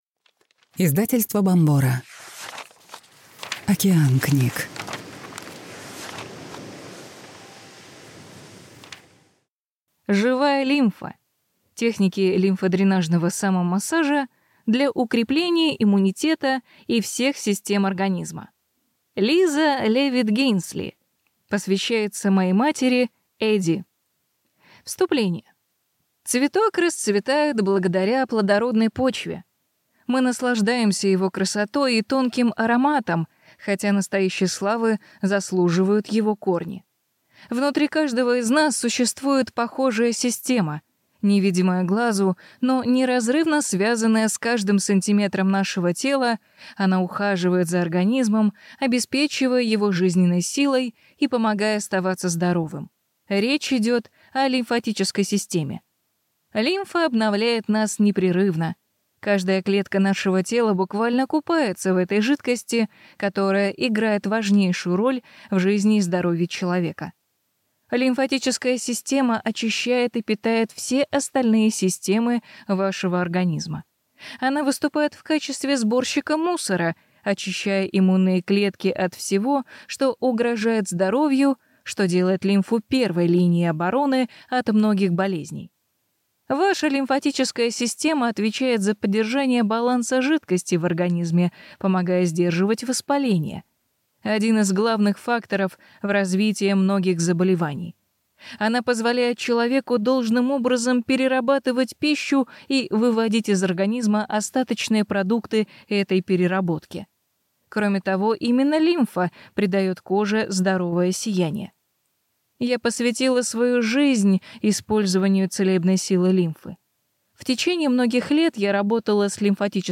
Аудиокнига Живая лимфа. Техники лимфодренажного самомассажа для укрепления иммунитета и всех систем организма | Библиотека аудиокниг